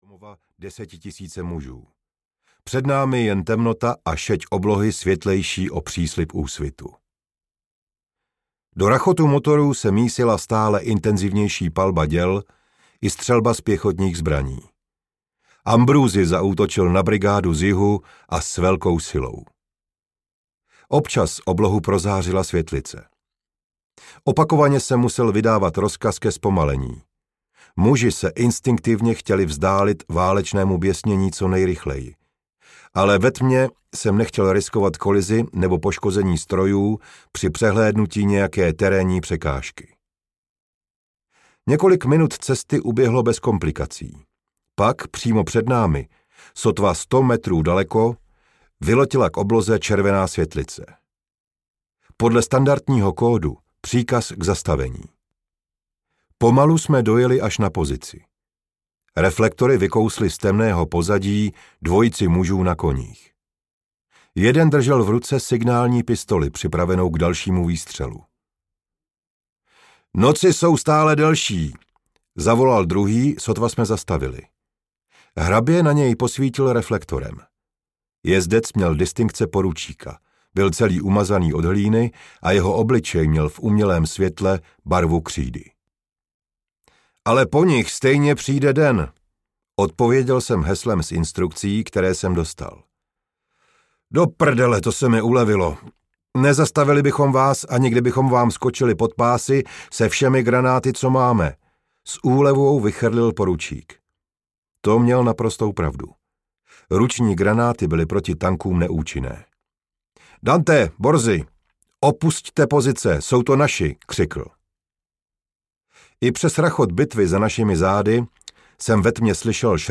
Válečník audiokniha
Ukázka z knihy